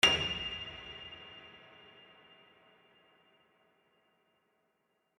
HardPiano